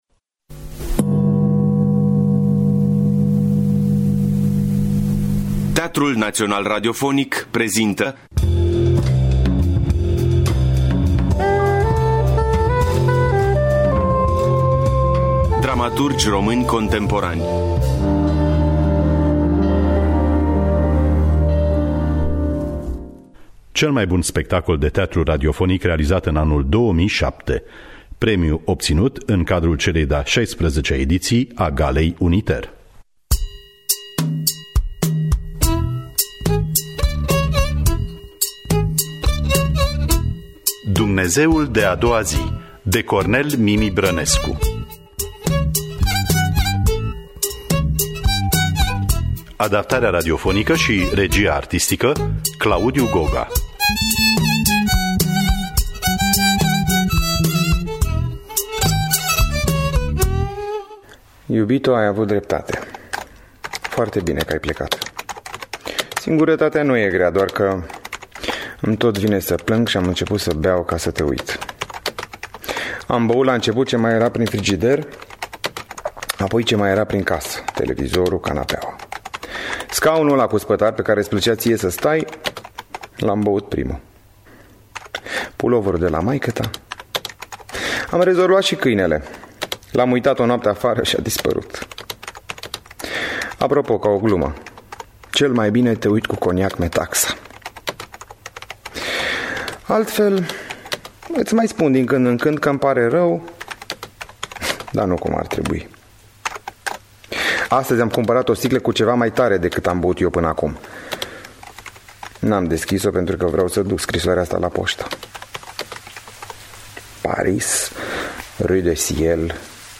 Adaptarea radiofonicã
În distribuţie: Medeea Marinescu şi Vlad Zamfirescu.